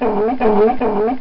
Sealion Sound Effect
Download a high-quality sealion sound effect.
sealion-2.mp3